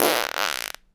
fart_squirt_08.wav